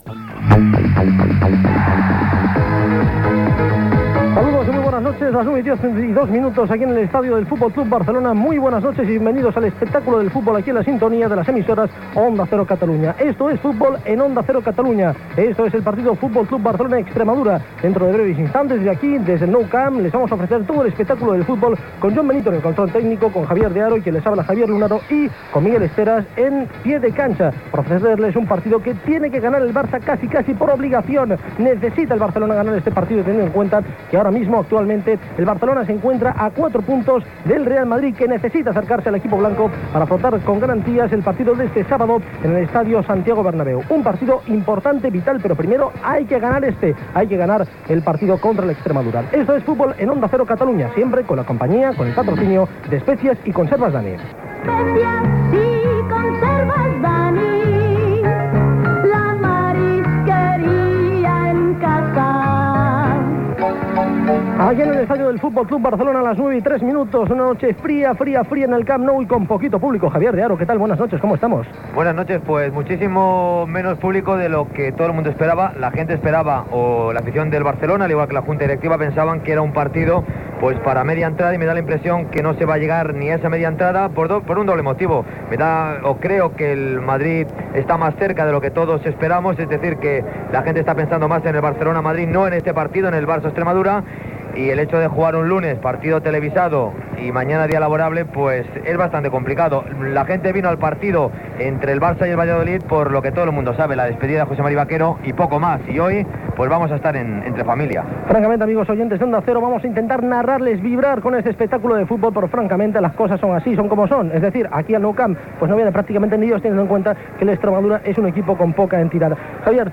Transmissió del partit de la Llliga de Primera Diviisó de futbol masculí entre el Futbol Club Barcelona i l'Extremadura. Hora, equip, presentació, ambient al Camp Nou, situació dels equips, aliniacions dels equips i comentaris previs al partit amb publictat. Narració de les primeres jugades de la primera part i del primer gol del Barça.